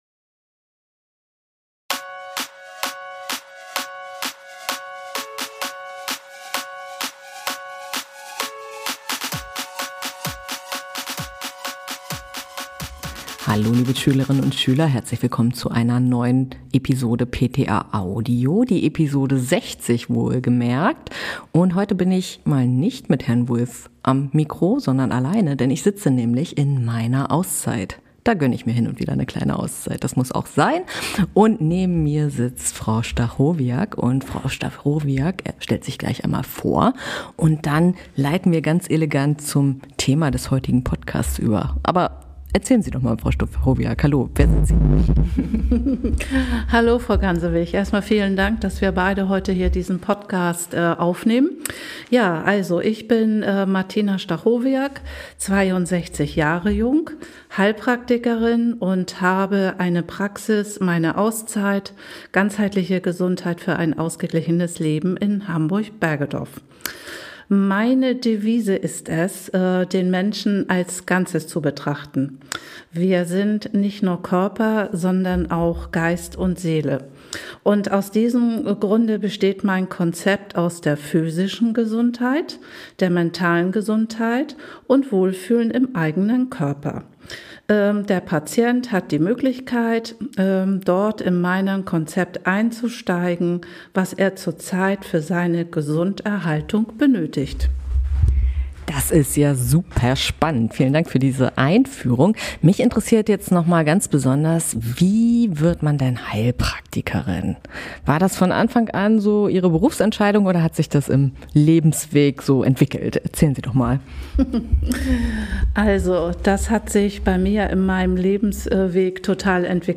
Episode 60 - Im Gespräch mit einer Heilpraktikerin - rund um Vitamin D ~ PTA-Audio Podcast